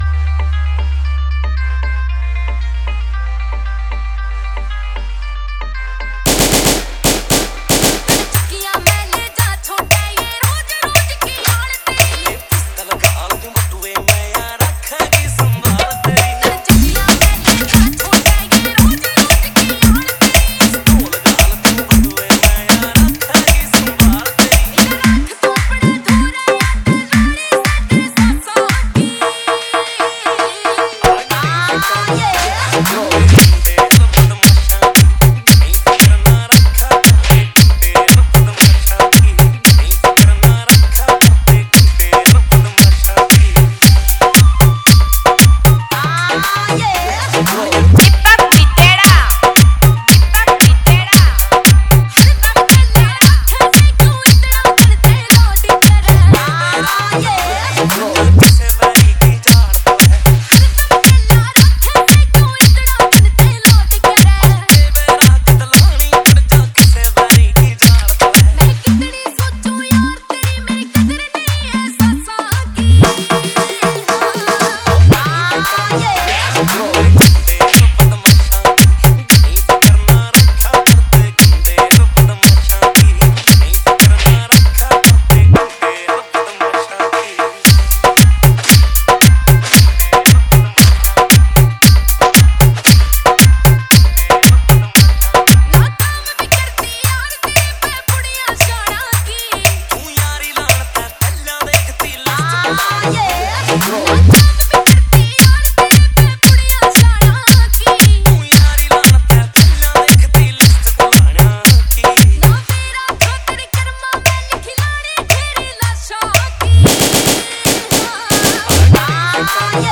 dj Song